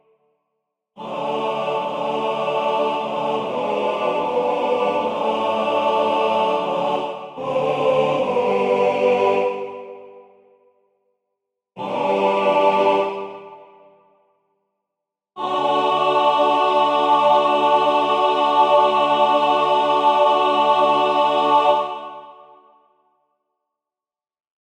The now-deleted notation has no effect on the playback, and the playback is as if those events were never removed.